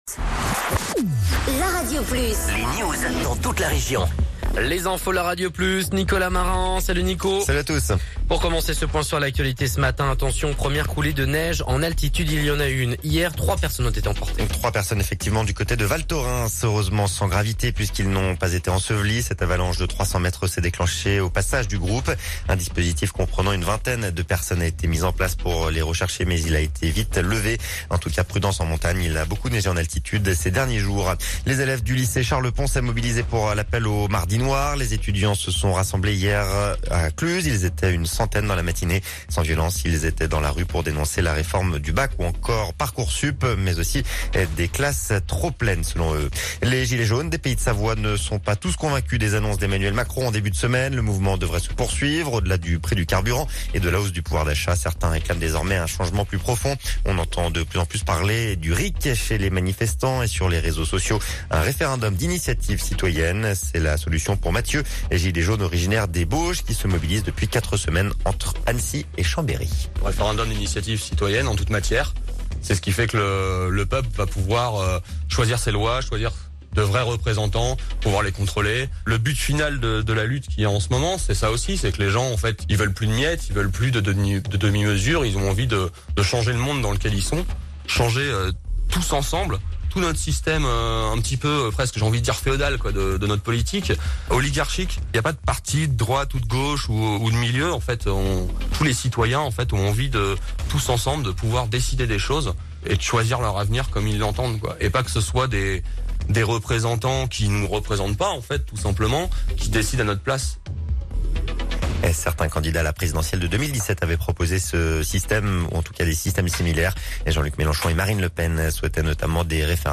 Reportage sur le schéma d'aménagement et de gestion des eaux en Haute-Savoie